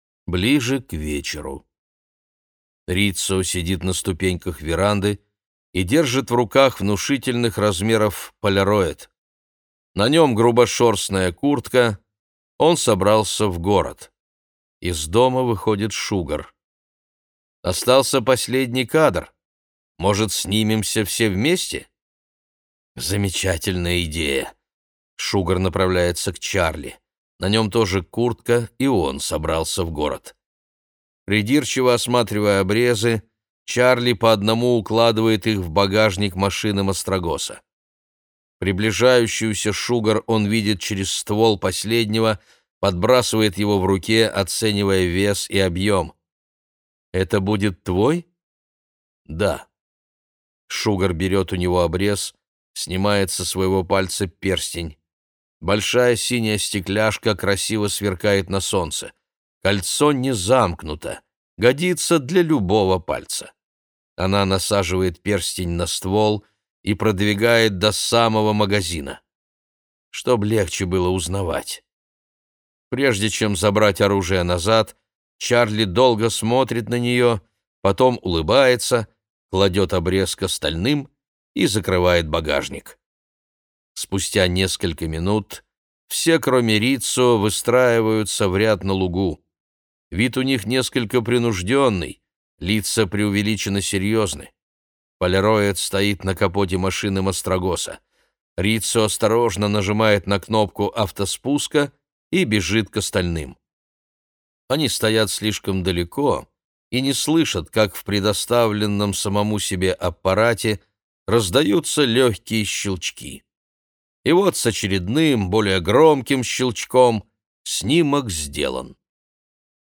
Аудиокнига Бег зайца через поля | Библиотека аудиокниг